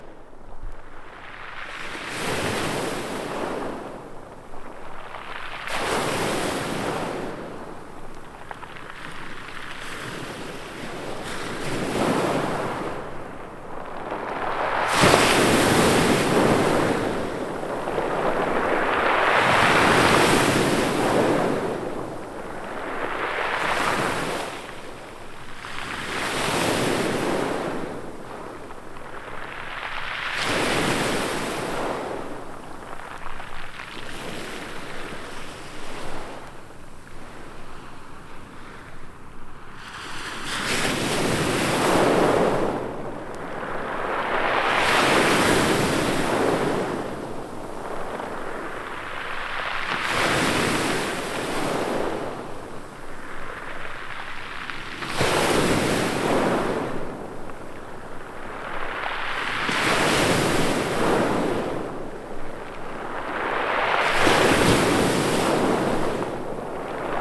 究極のアンビエント！
様々な『波』が8種収録。